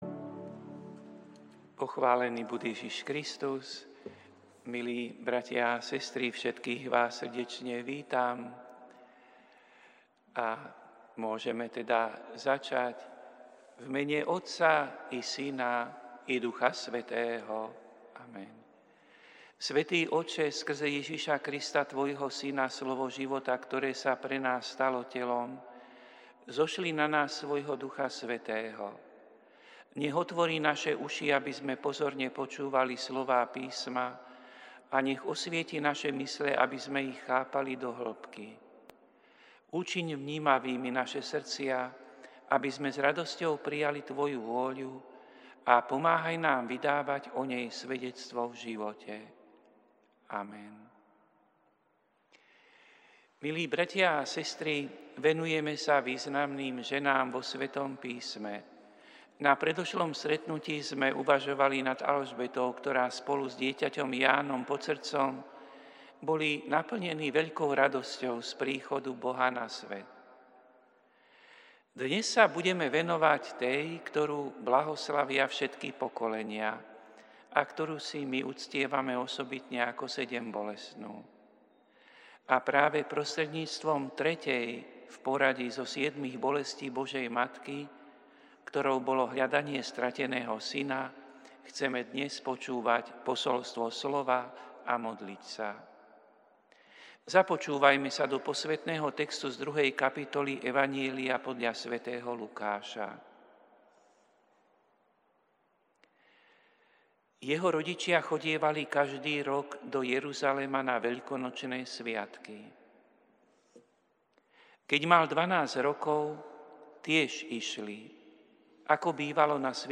Prinášame plný text a audio záznam z Lectio divina, ktoré odznelo v Katedrále sv. Martina 4. marca 2026.